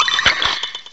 cry_not_vanillish.aif